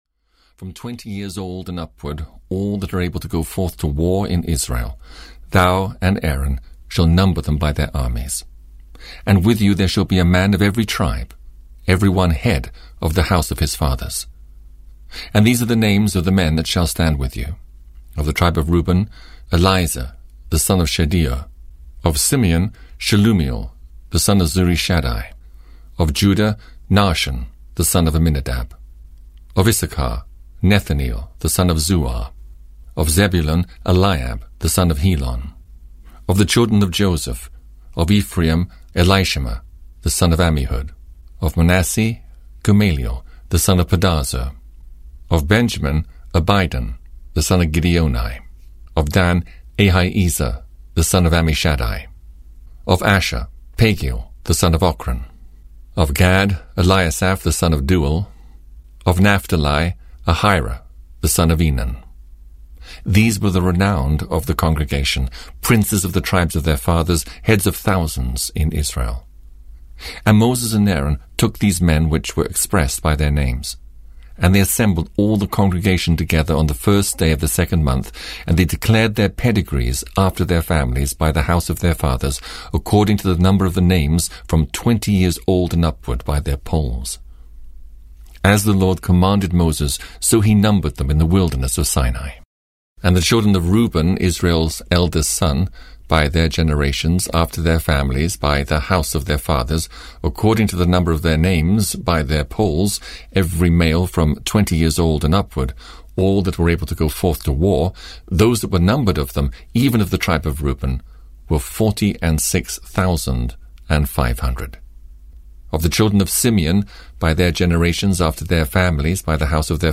The Old Testament 4 - Numbers (EN) audiokniha